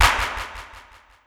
Bricks Clap.wav